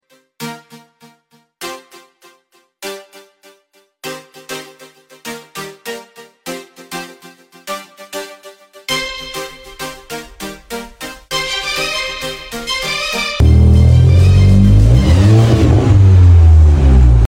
Cupra Formentor Vz line arka egzoz silme Y pipe & akrapovic custom egzoz uygulama